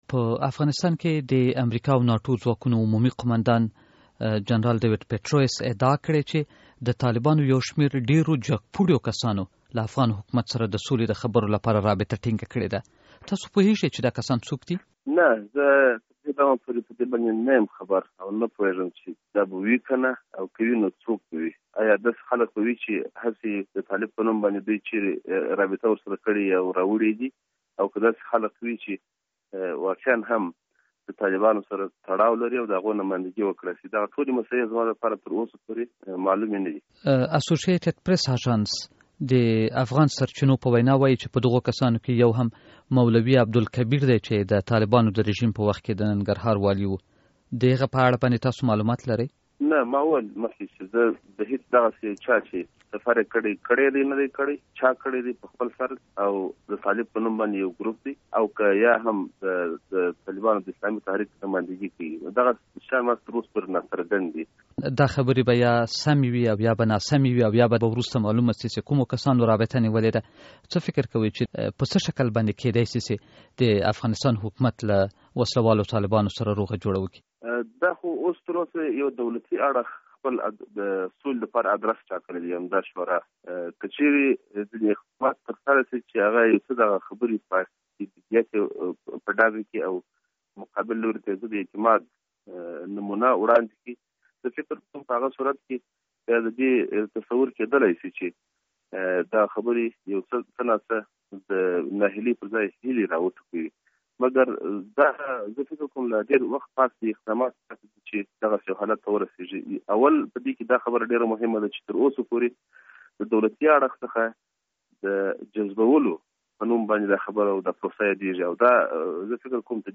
له مولوي متوکل سره مرکه